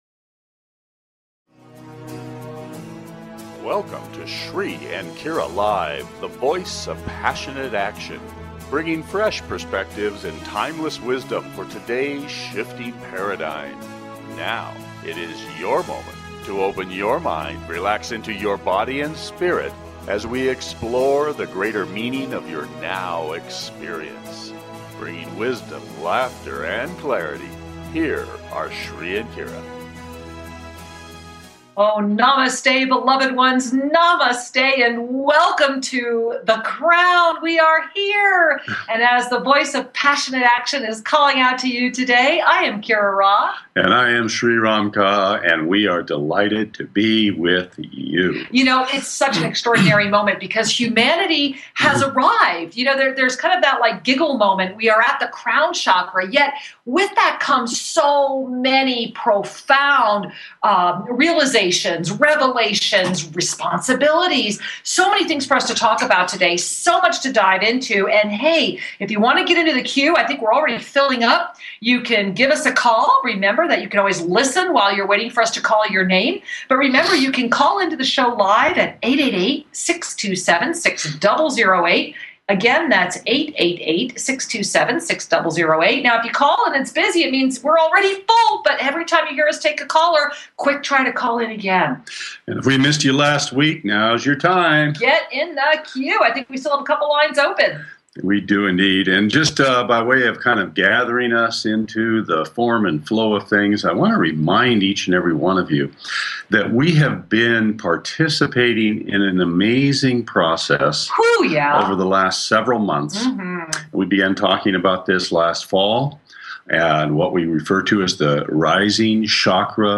Talk Show Episode
The lines are open, so call in with your questions, comments and mini soul reading requests!